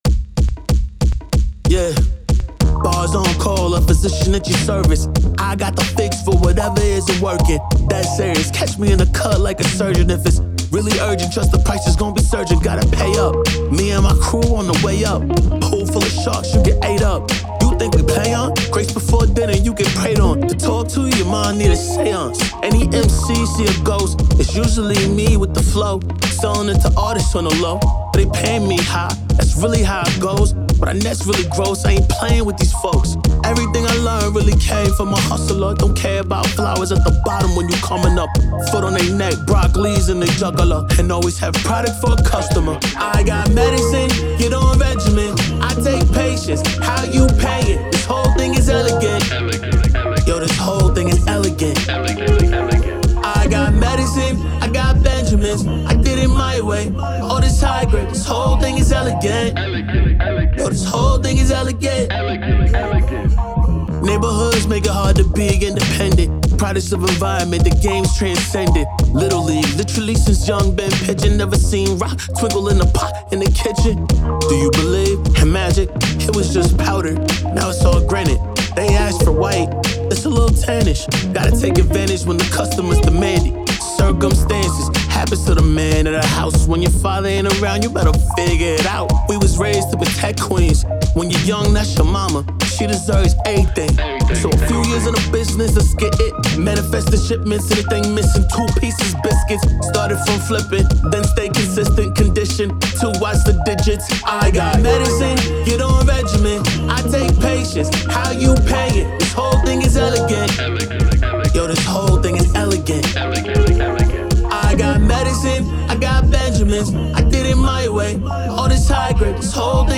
R&B, Hip Hop, 80s, 90s
D#minor
Conscious Rap